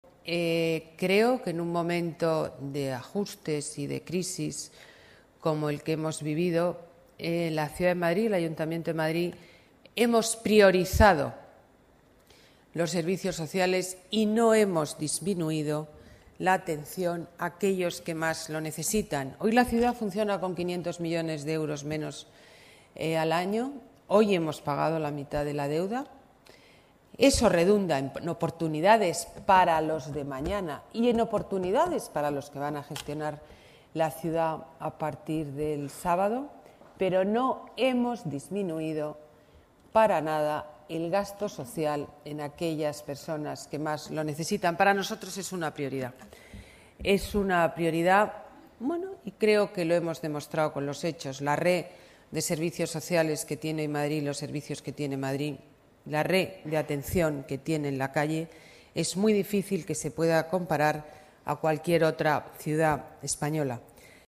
ABotellaDeclaracionesSamurSocial-09-06.mp3